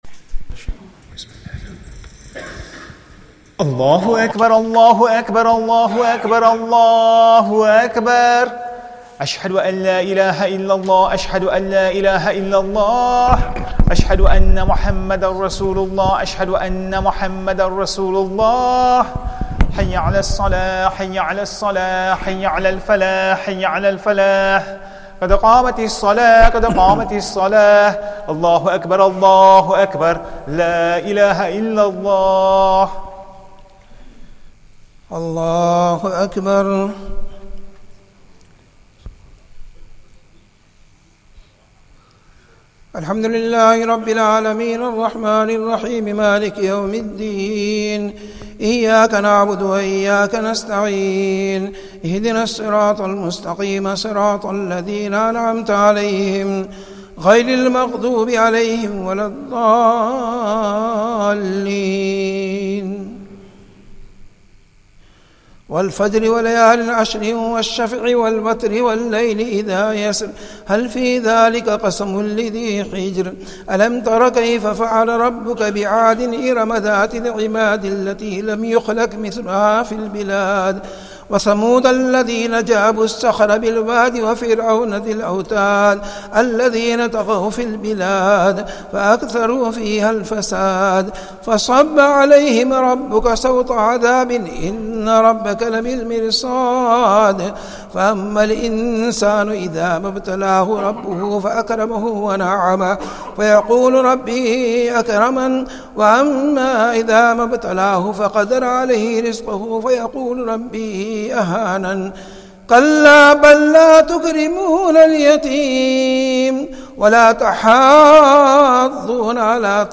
Ramadan 1444 | Taraweeh 20